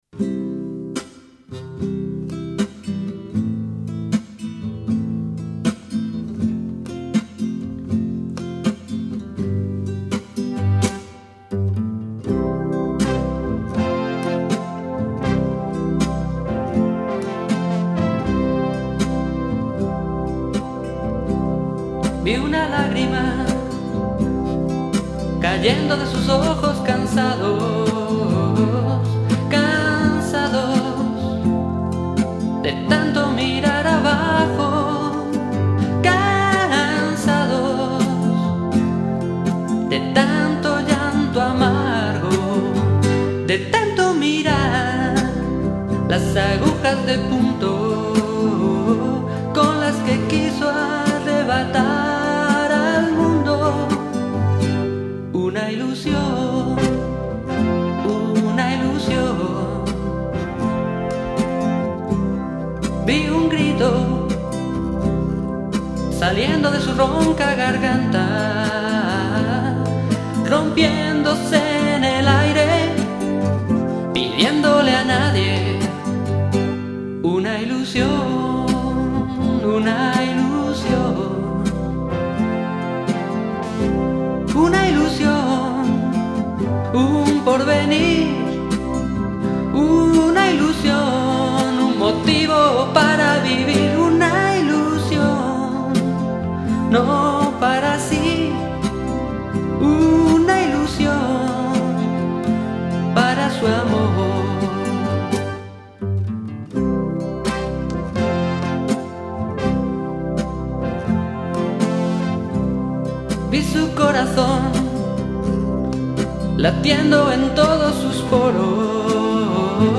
Versión de estudio.